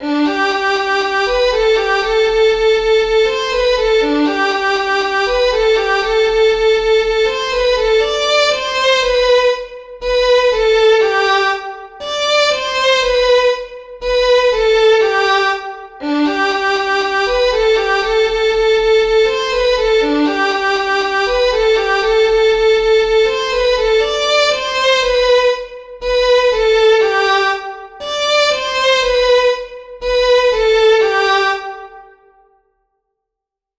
ŽIDOVKA tempo 120